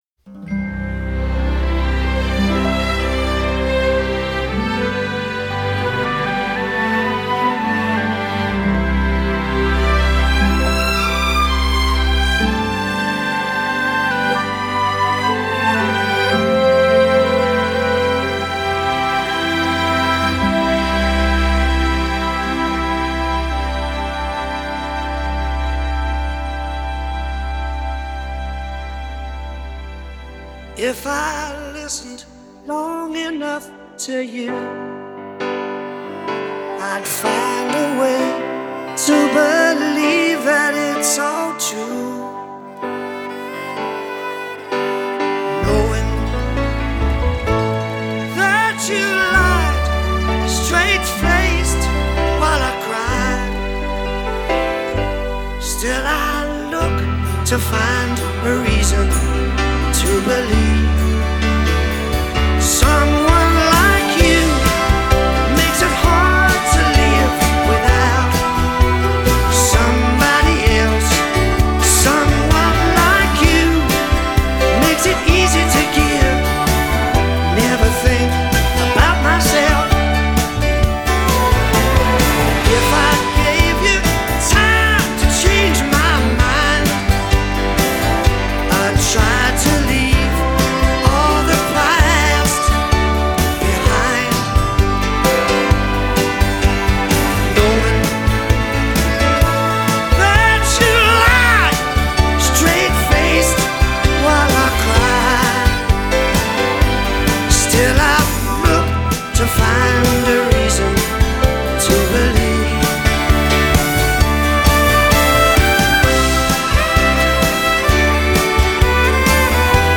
• Genre: Rock, Pop